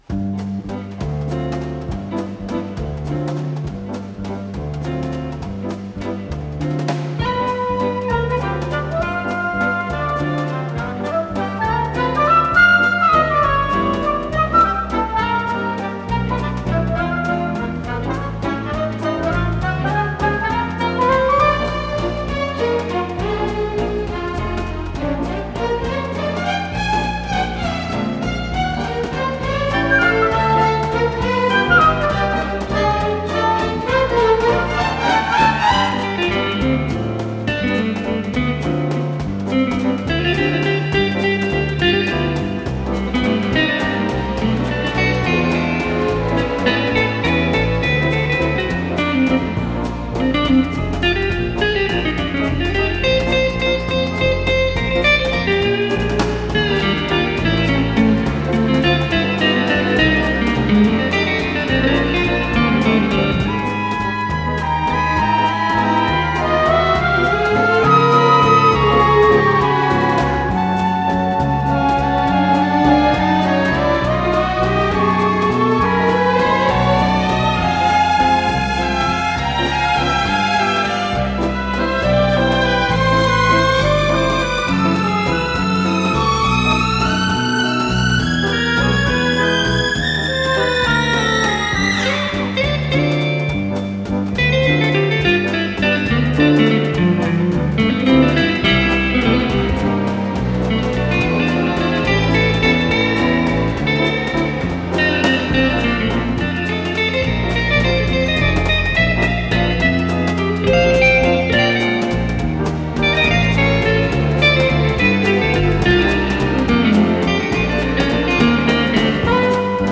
с профессиональной магнитной ленты
Соло на гитаре
ВариантДубль моно